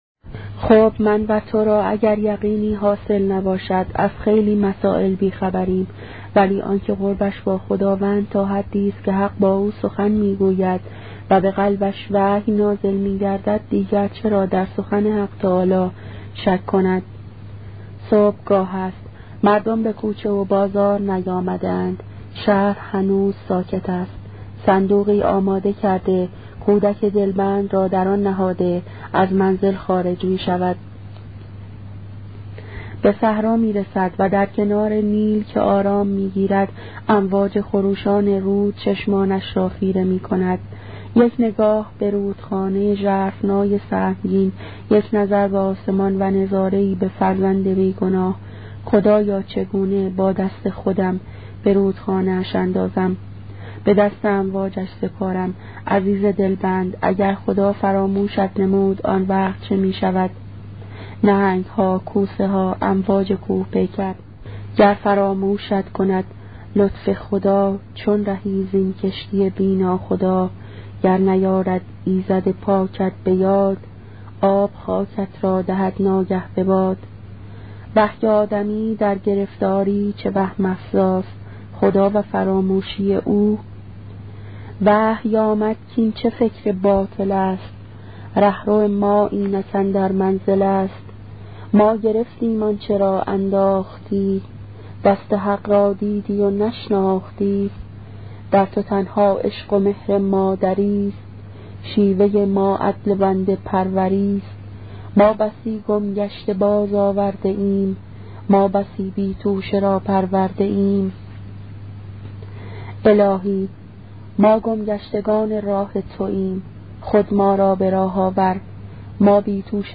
کتاب صوتی عبادت عاشقانه , قسمت هشتم